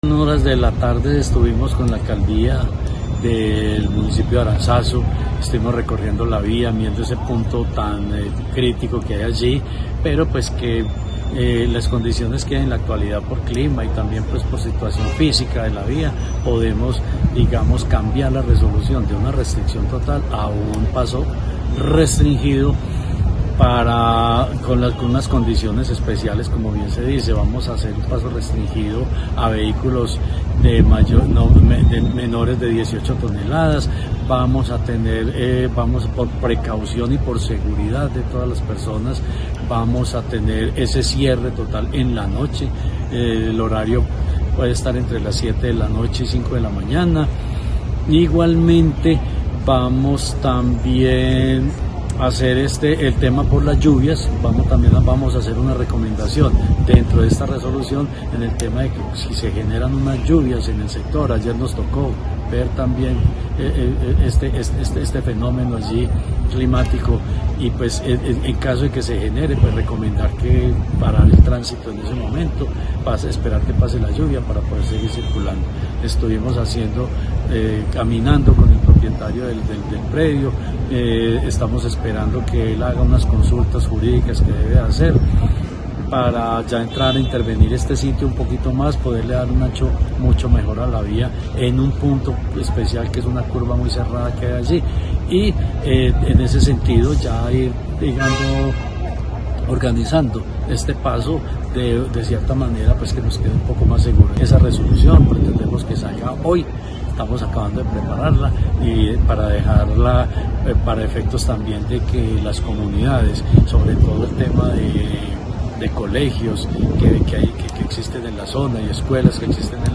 Jorge Ricardo Gutiérrez, secretario de Infraestructura de Caldas.